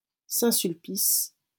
The Church of Saint-Sulpice (French pronunciation: [sɛ̃ sylpis]